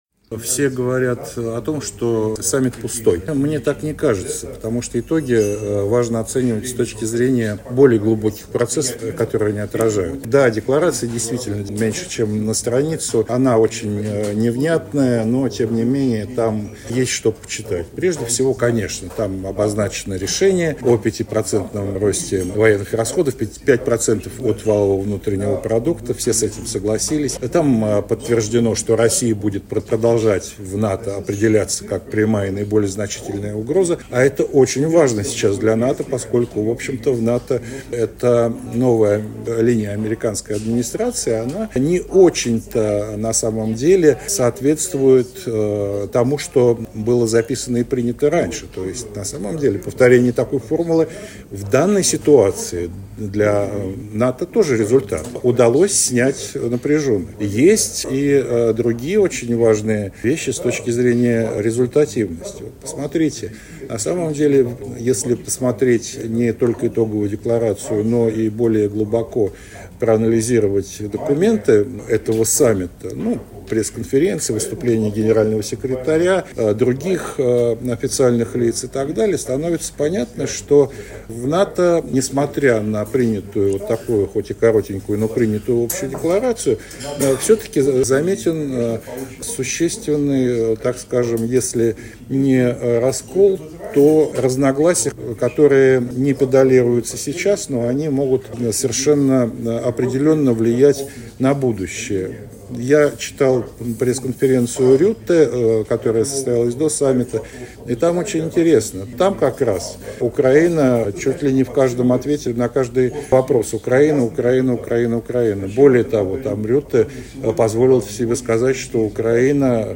интервью журналу «Международная жизнь»